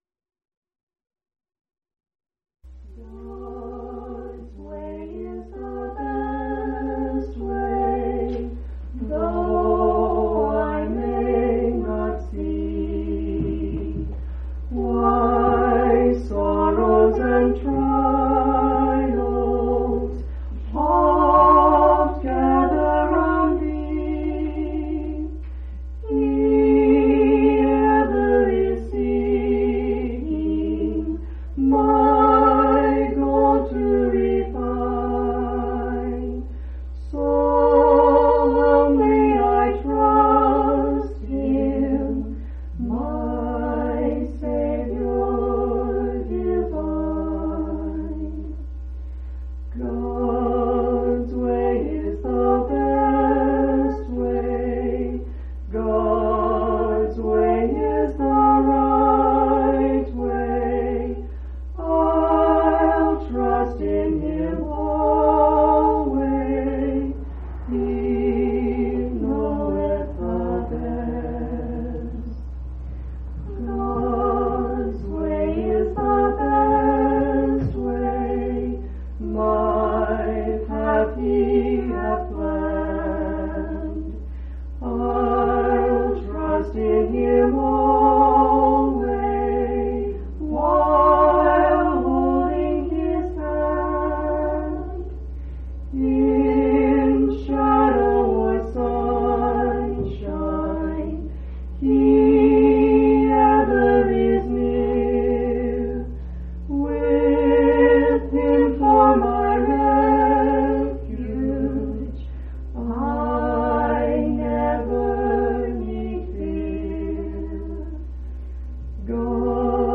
6/9/1995 Location: Colorado Reunion Event